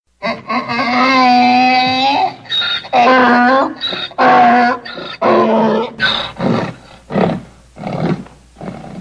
دانلود آهنگ حیوانات 12 از افکت صوتی انسان و موجودات زنده
دانلود صدای حیوانات 12 از ساعد نیوز با لینک مستقیم و کیفیت بالا
جلوه های صوتی
برچسب: دانلود آهنگ های افکت صوتی انسان و موجودات زنده دانلود آلبوم مجموعه صدای حیوانات مختلف با سبکی خنده دار از افکت صوتی انسان و موجودات زنده